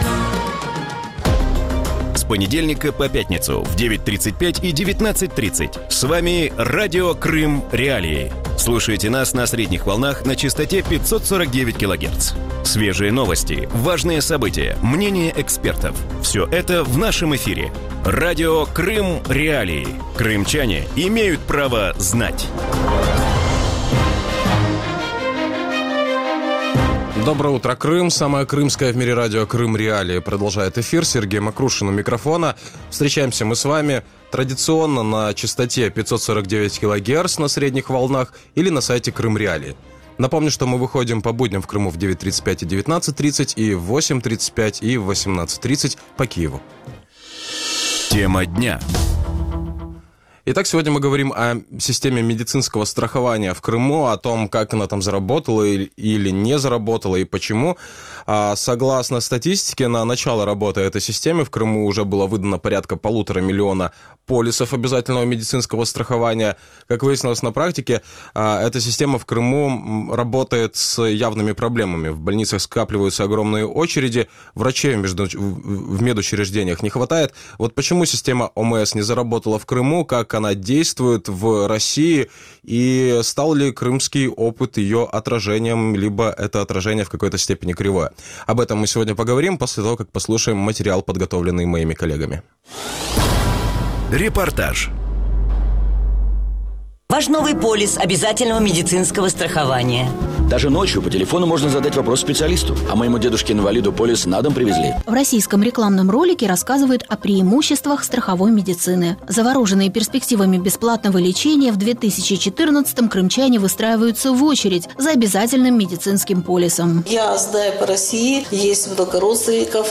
У ранковому ефірі Радіо Крим.Реалії говорять про медичне страхування в Криму.